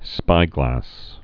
(spīglăs)